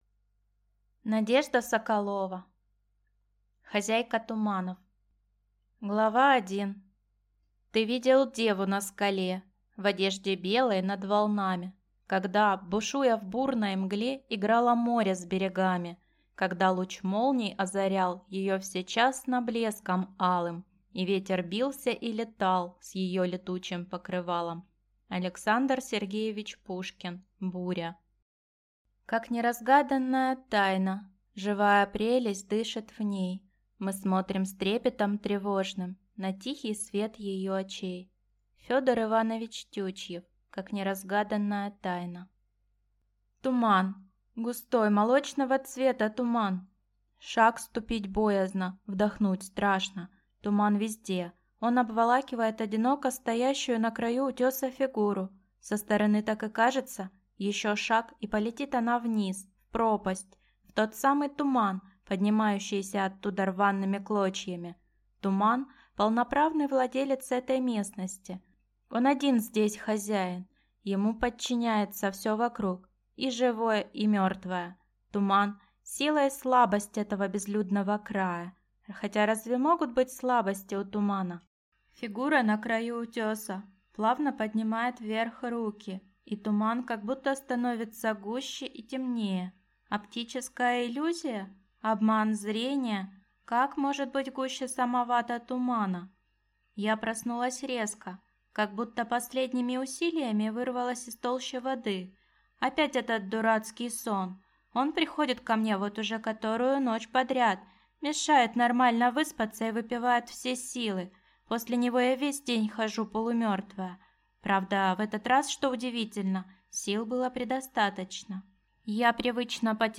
Аудиокнига Хозяйка туманов | Библиотека аудиокниг